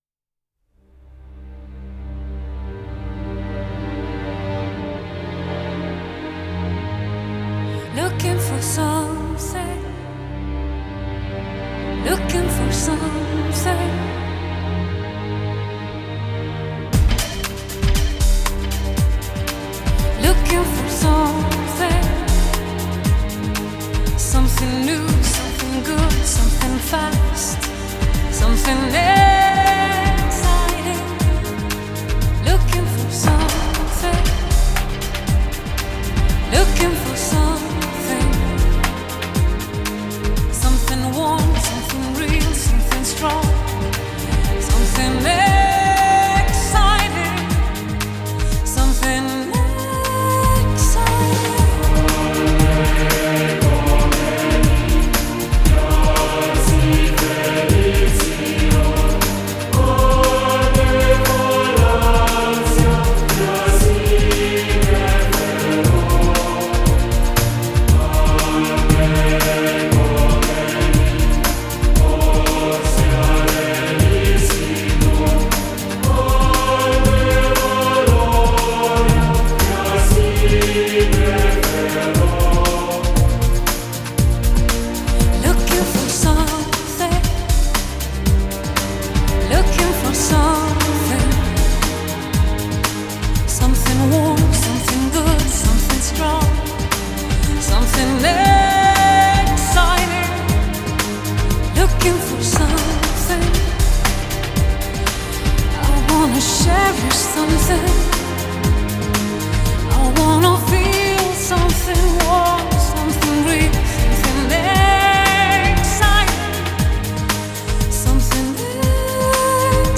Genre : Ambient, Enigmatic, New Age